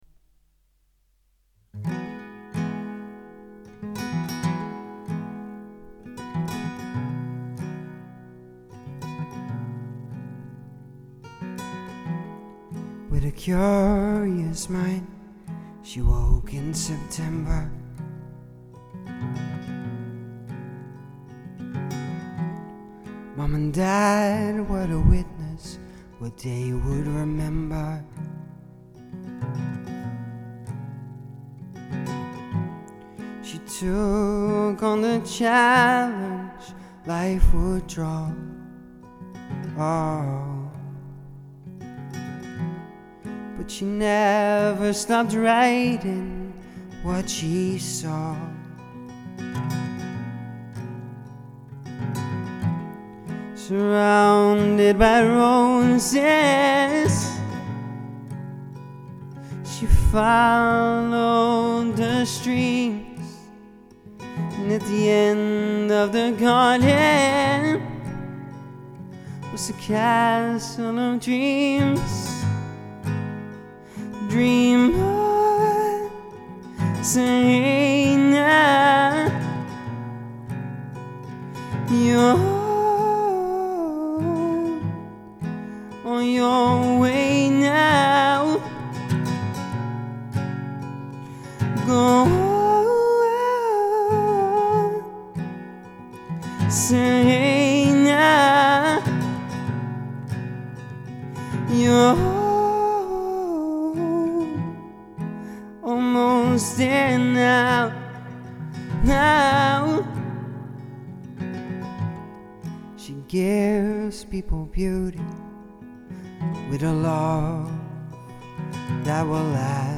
singer/songwriter